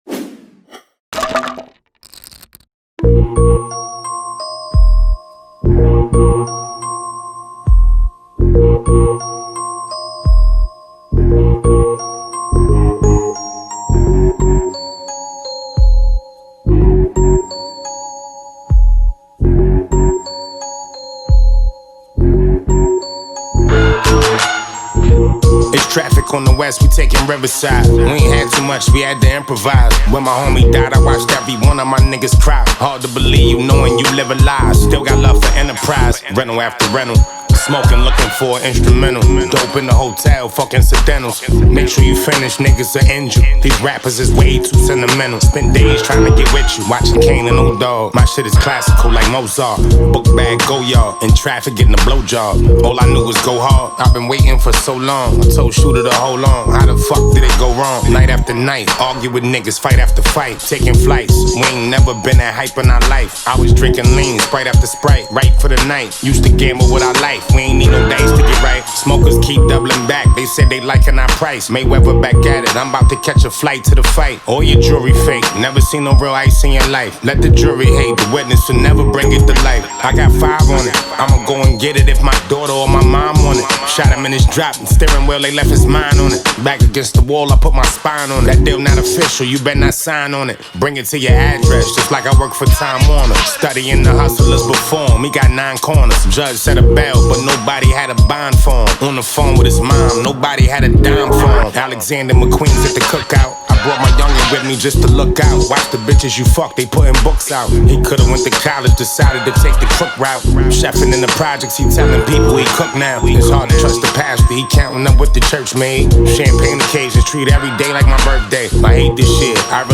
takes a classic beat and gives his own spin on it